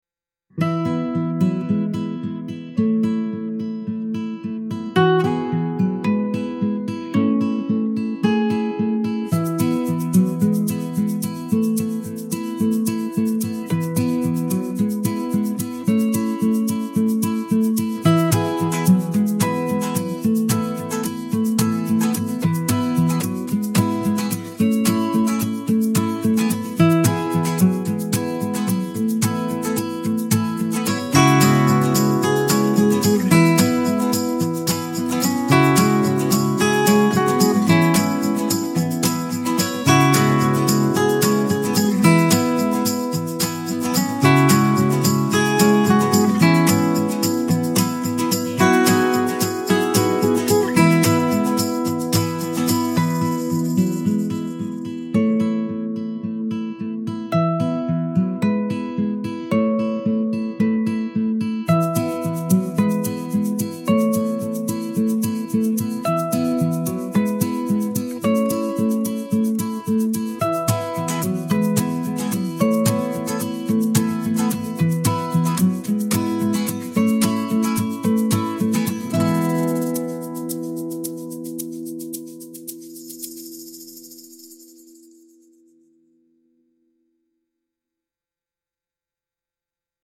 warm indie folk with group singing feel, acoustic guitars and tambourine